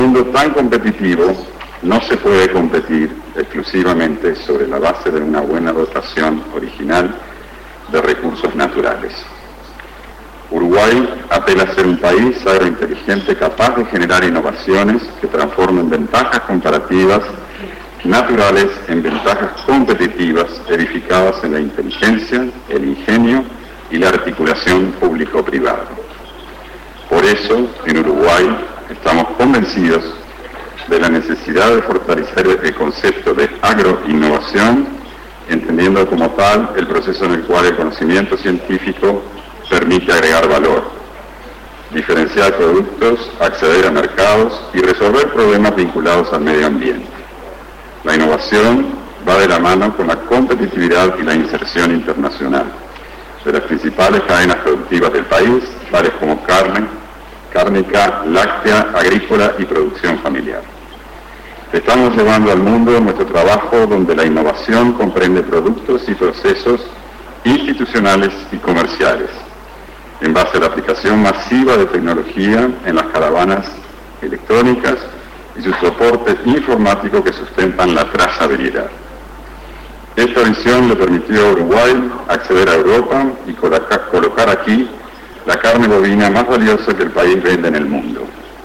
Se realizó en el Hotel Hyatt Regency Köln el Festival de la Carne Uruguaya, donde se presenta en el menú del Restaurante dos platos de carne bovina y ovina.
Embajador Alberto Guani. mp3. 1:28